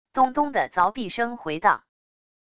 咚咚的凿壁声回荡wav音频